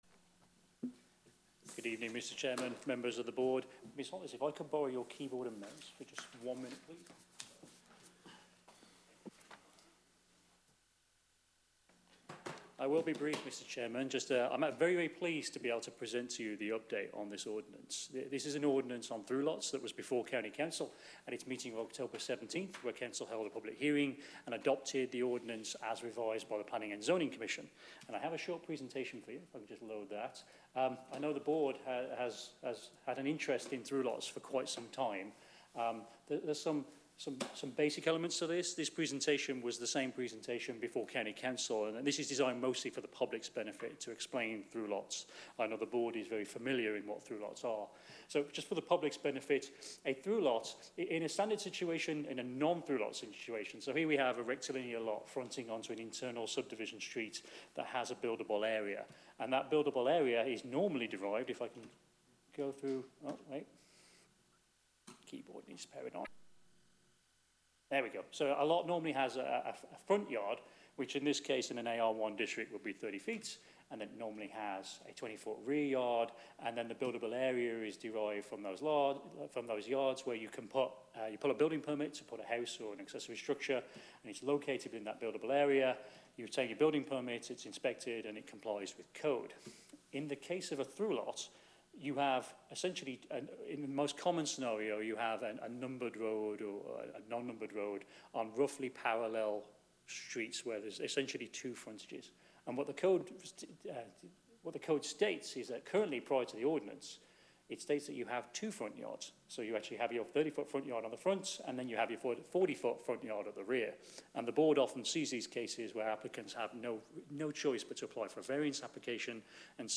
Board of Adjustment Meeting
Council Chambers, Sussex County Administrative Office Building, 2 The Circle, Georgetown